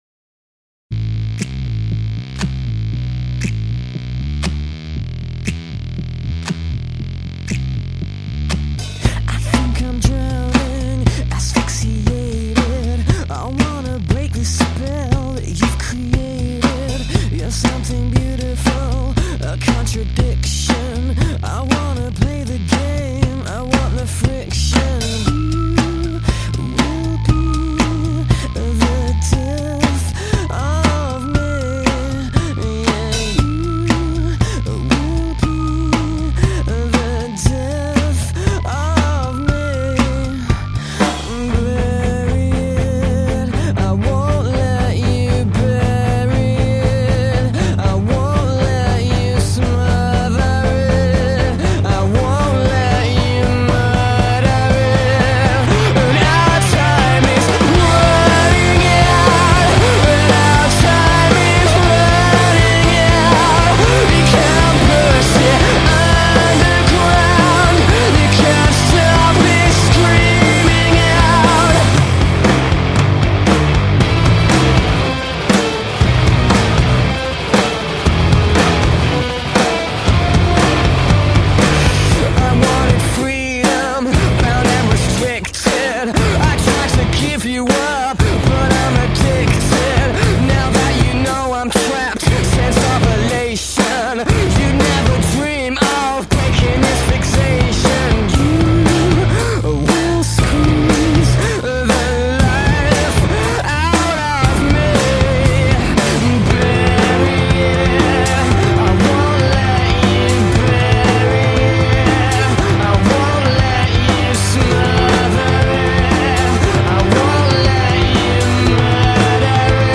rock bands
vocals-guitar-keyboards
bass guitar
drums-percussion